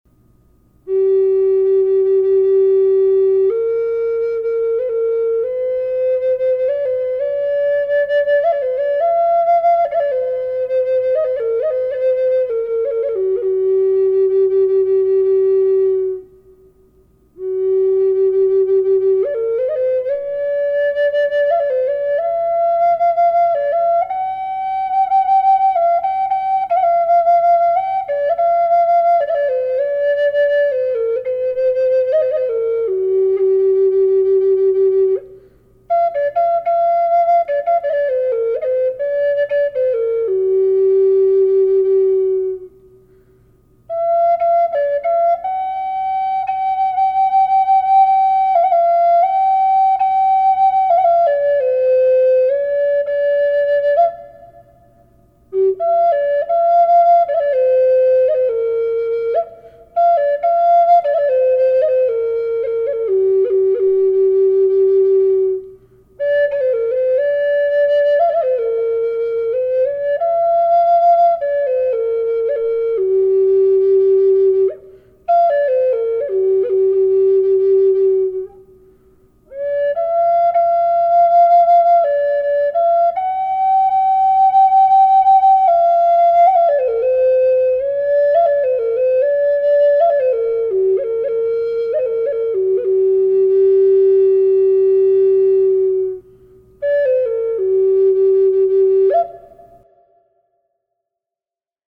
Handcrafted from Kachina Peaks Aspen wood and Sedona Red Manzanita,
Listen to G A432Hz frequency
Light reverb added
vortex-432-reverb-g-minor.mp3